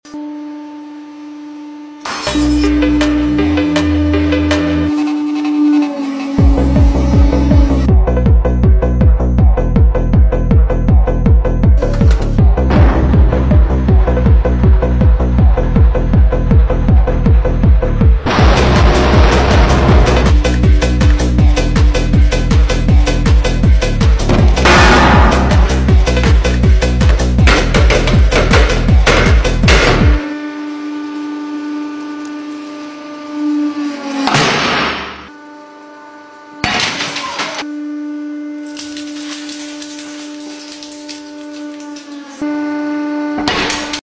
Crushing Candy and marble balls